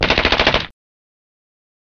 دانلود صدای تفنگ 6 از ساعد نیوز با لینک مستقیم و کیفیت بالا
جلوه های صوتی